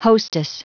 Prononciation du mot hostess en anglais (fichier audio)
Prononciation du mot : hostess